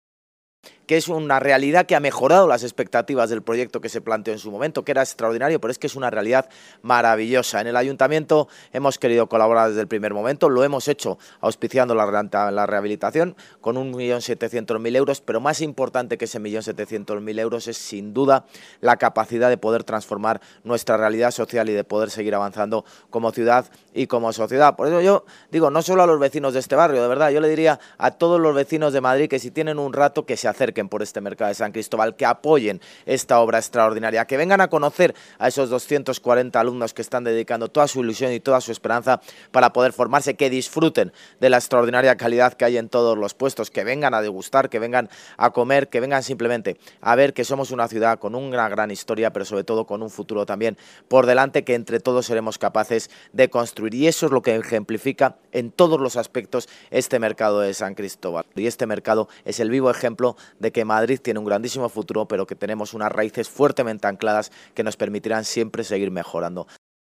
Nueva ventana:Intervención del alcalde de Madrid, José Luis Martínez-Almeida, en el acto de inauguración del Mercado Municipal de San Cristóbal